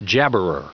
Prononciation du mot jabberer en anglais (fichier audio)
Prononciation du mot : jabberer